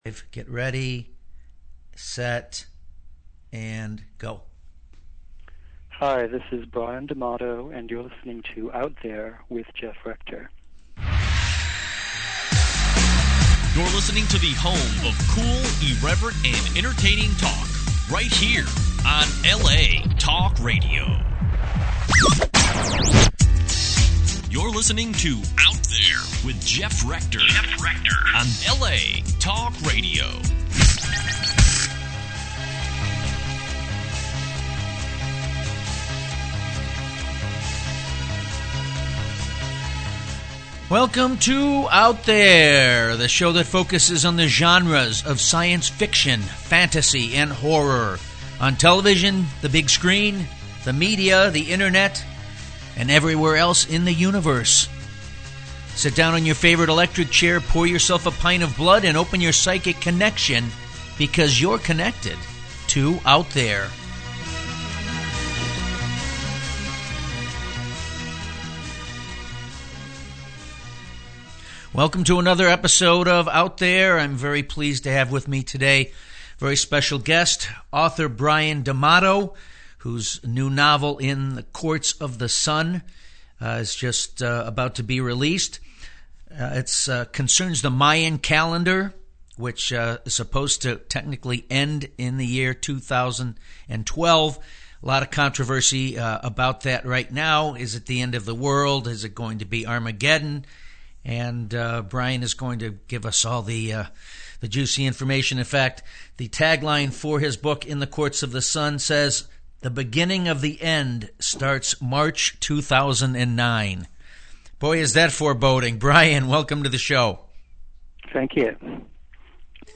In the Courts of the Sun - Interviews